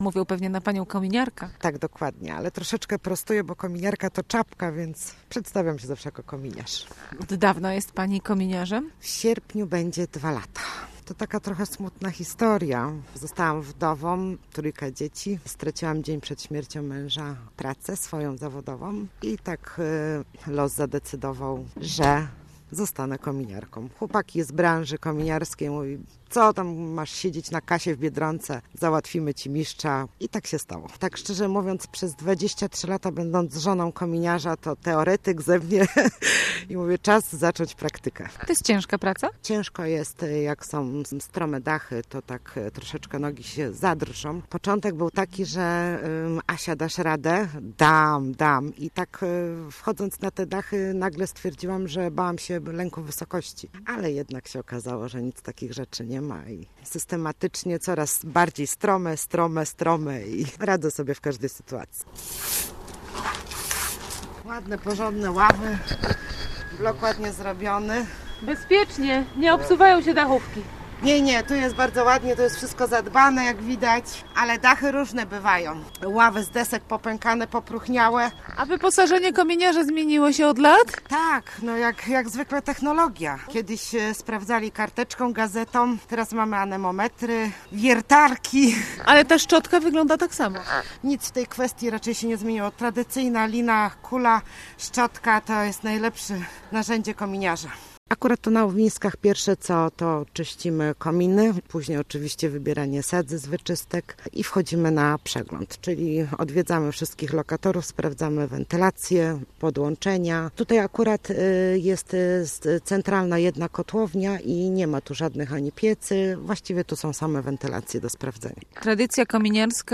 4jytgcu2d7lm4d9_rozmawiala-z-kominiarka-na-dachu.mp3